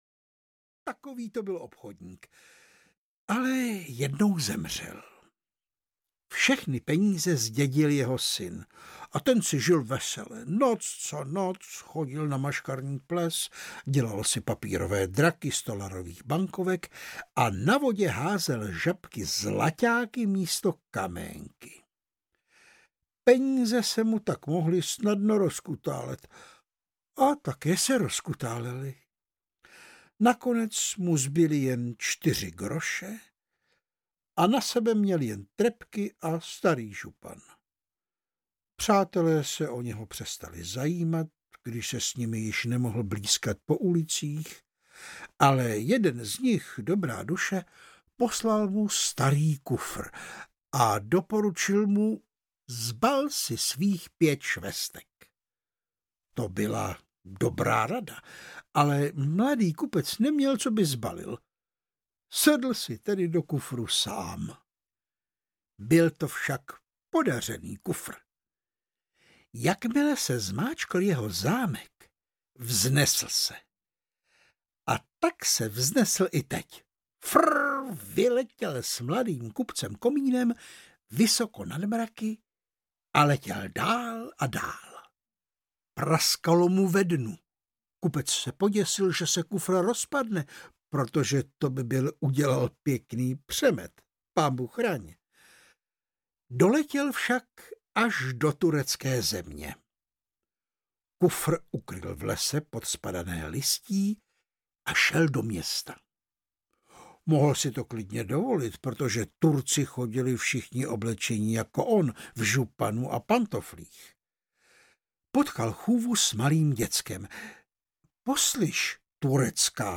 Létající kufr audiokniha
Ukázka z knihy
• InterpretVáclav Knop